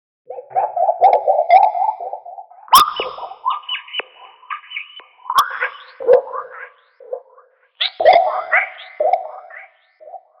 男子汉大丈夫声情并茂
描述：一个古怪的声乐循环
Tag: 120 bpm Weird Loops Vocal Loops 288.74 KB wav Key : Unknown